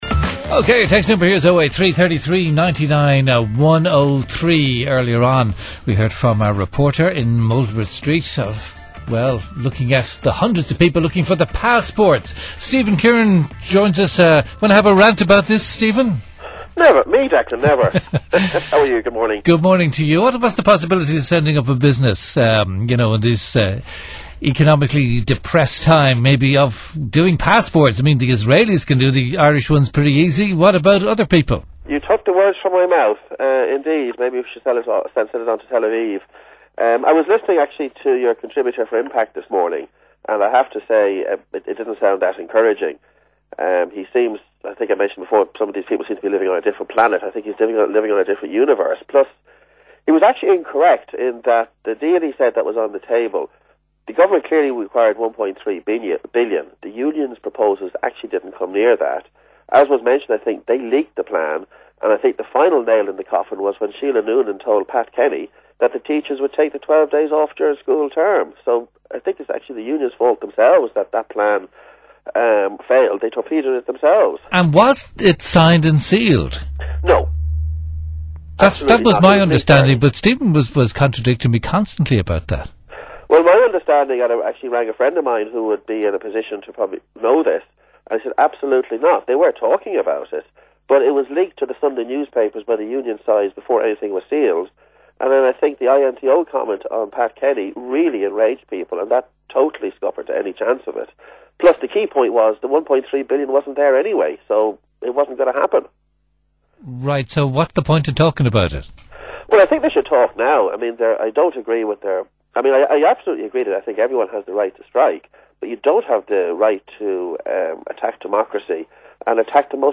Categorized | Radio Interviews Public Service Action in Passport Office Posted on 23/03/2010.